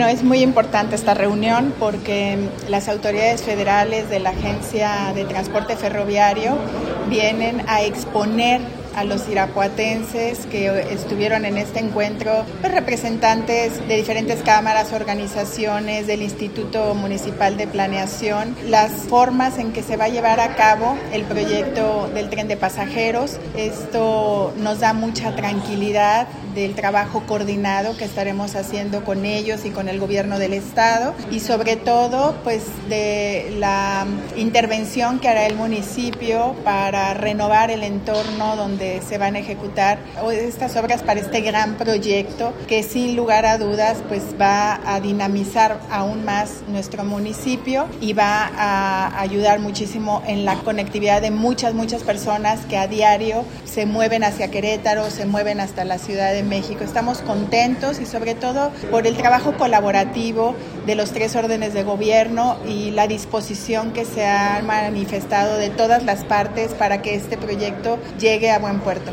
AudioBoletines
Lorena Alfaro, Presidenta Municipal